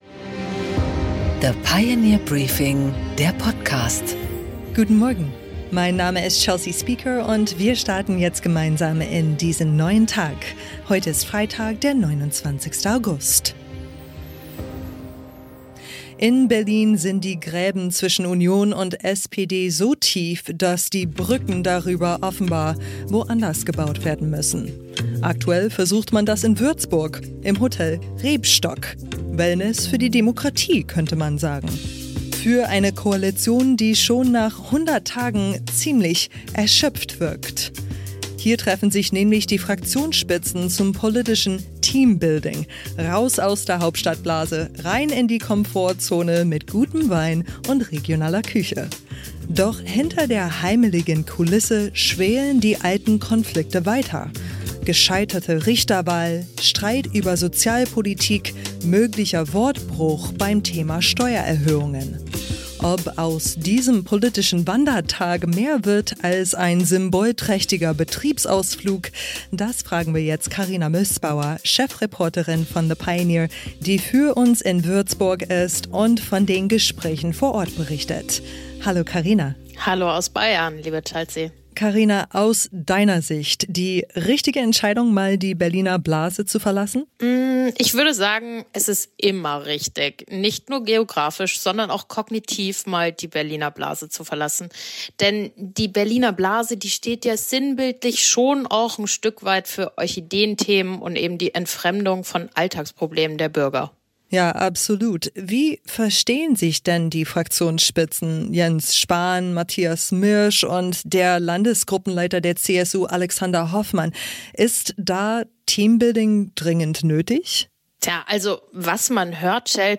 Im Gespräch: CDU-Außenpolitiker Roderich Kiesewetter über die massiven Drohnenangriffe Russlands auf die EU Vertretung und den British Council in Kiew – und was Europa jetzt tun muss, um Putin klar zu begegnen.
Interview mit Roderich Kiesewetter